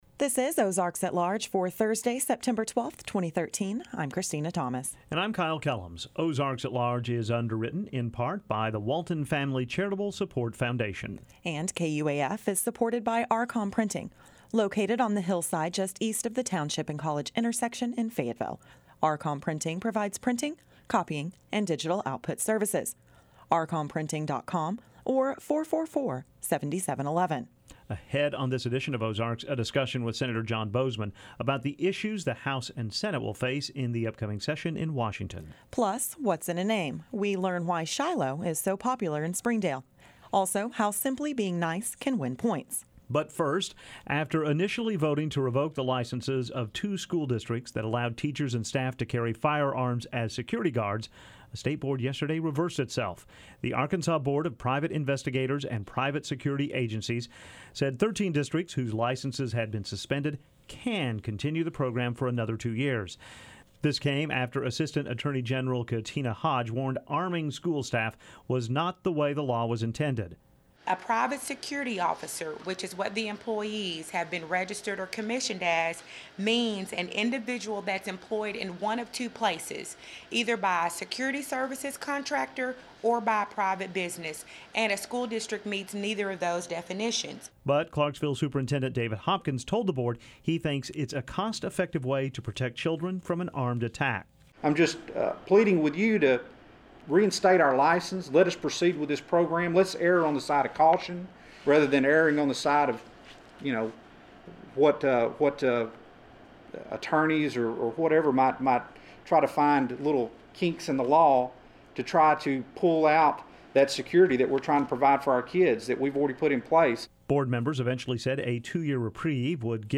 Transition Music: